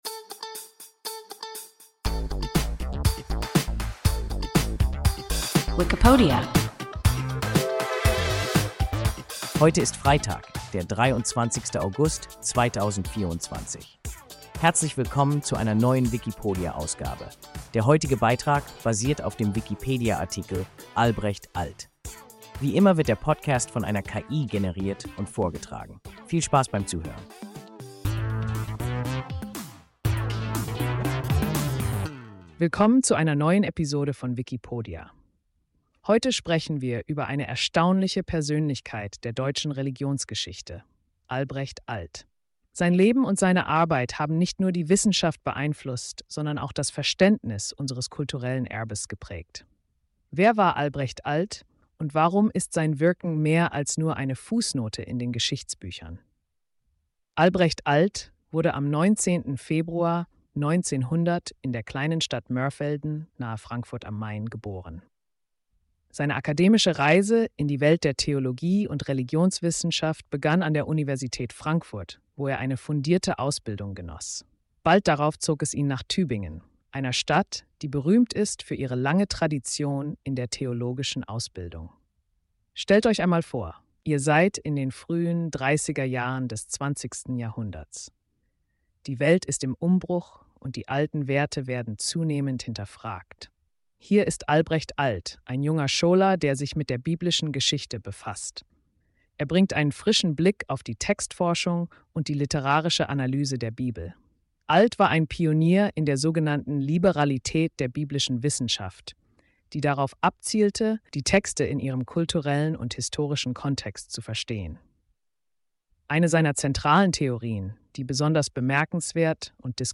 Albrecht Alt – WIKIPODIA – ein KI Podcast